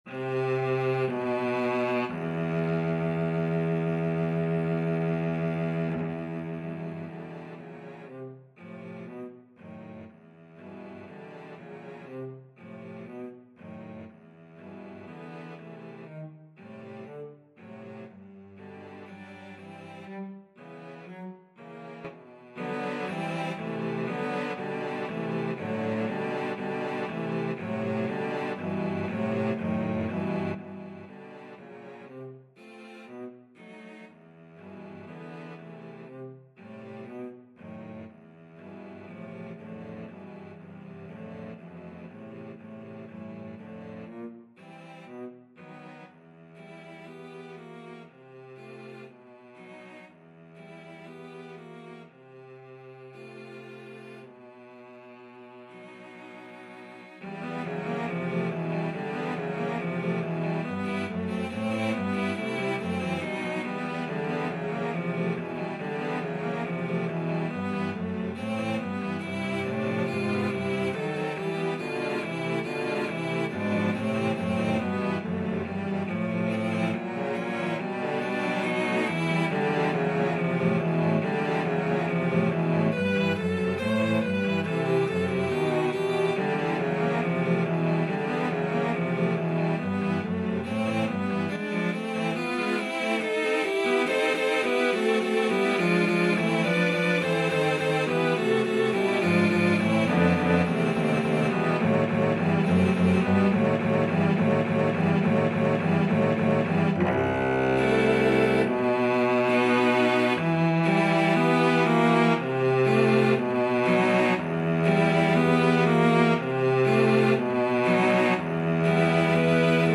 Cello 1Cello 2Cello 3Cello 4Cello 5
4/4 (View more 4/4 Music)
E minor (Sounding Pitch) (View more E minor Music for Cello Ensemble )
Lento
Cello Ensemble  (View more Intermediate Cello Ensemble Music)
Classical (View more Classical Cello Ensemble Music)